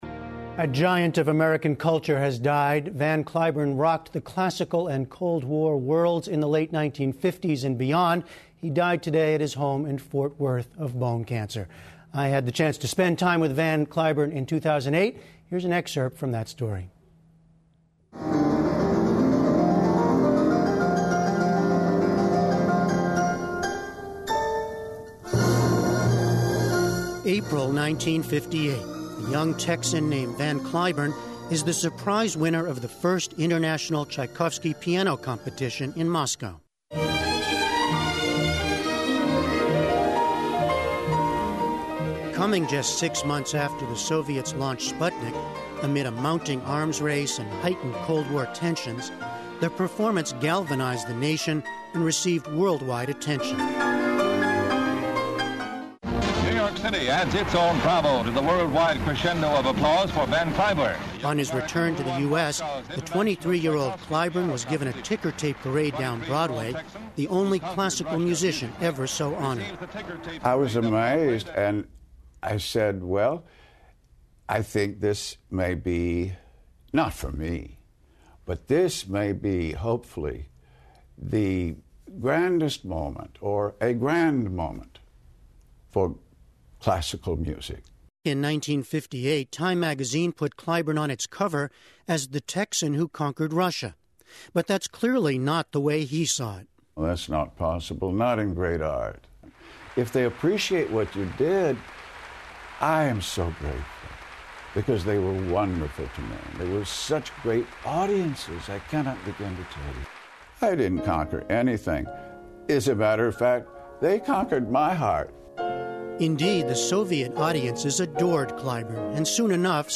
英语访谈节目:铭记古典钢琴家范·克莱本